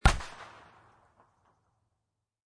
Descarga de Sonidos mp3 Gratis: explosion petardo 2.